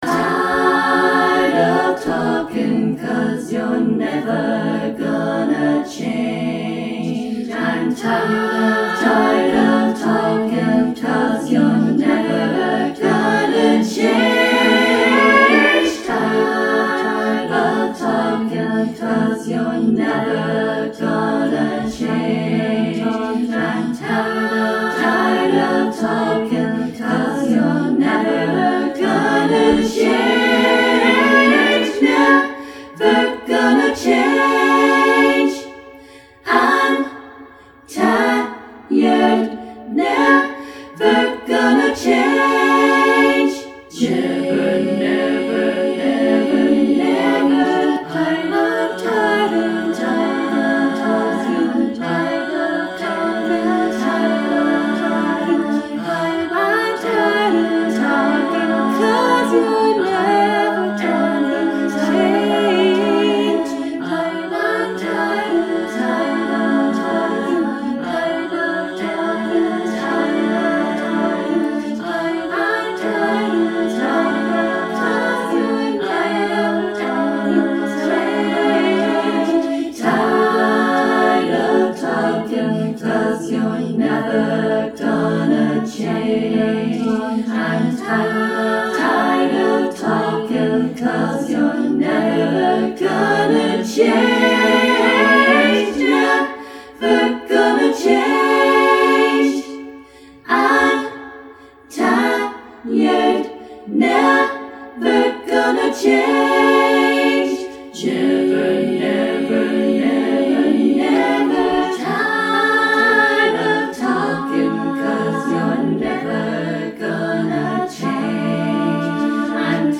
Lyric consists of only one sentence.
Very nice vocal work!